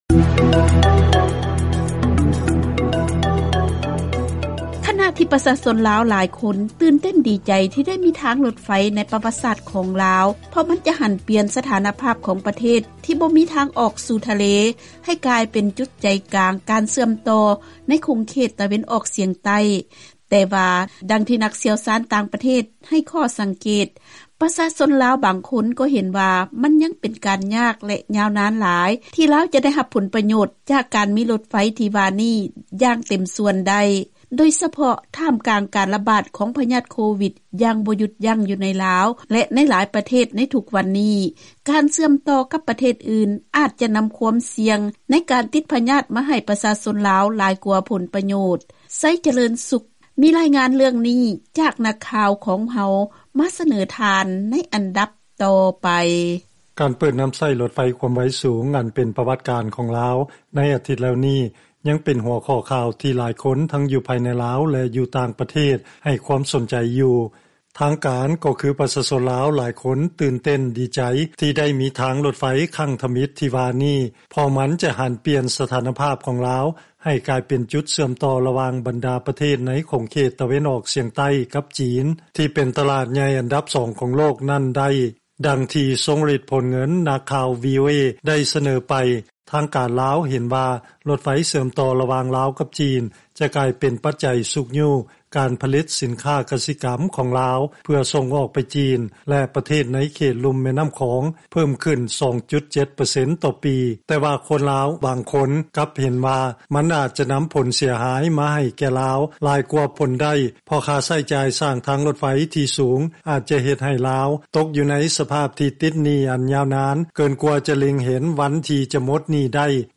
ເຊີນຟັງລາຍງານ ນັກຊ່ຽວຊານຕ່າງປະເທດ ໃຫ້ຂໍ້ສັງເກດ ມັນຍັງເປັນການຍາກ ແລະຍາວນານຫລາຍ ທີ່ລາວ ຈະໄດ້ຮັບຜົນປະໂຫຍດຈາກການມີລົດໄຟ